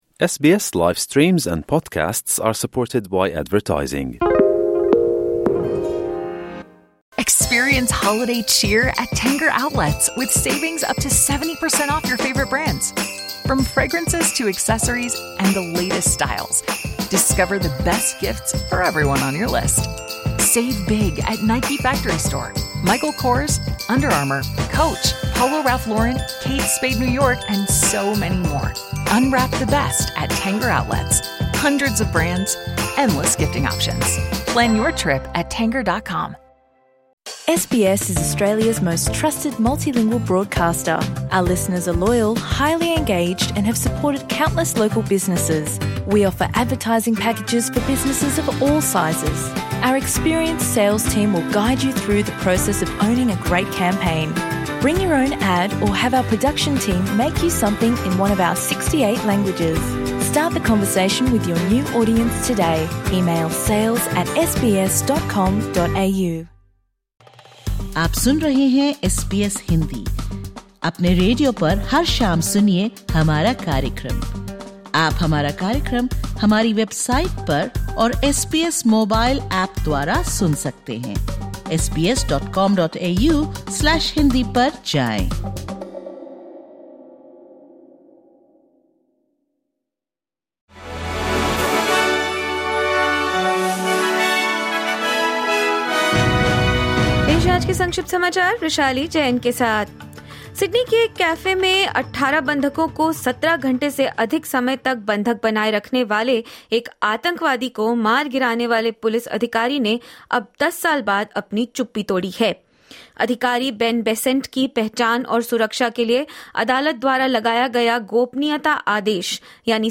1 Evening News Bulletin 15 December 2024 5:26